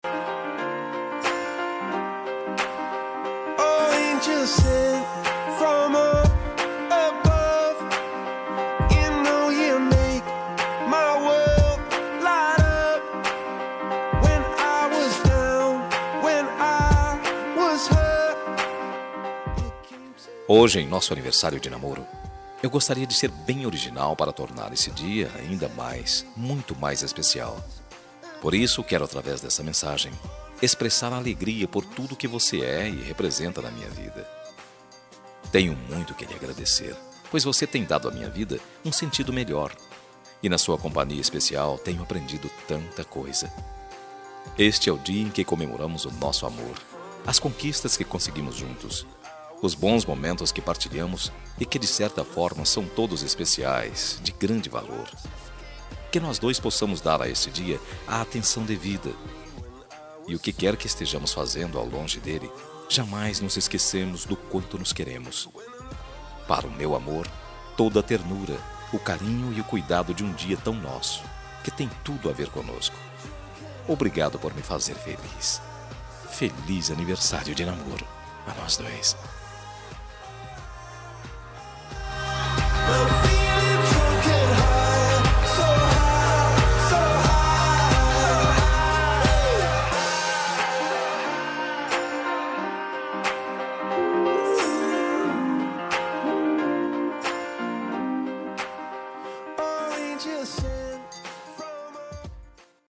Telemensagem Aniversário de Namoro – Voz Masculina – Cód: 8103- Linda.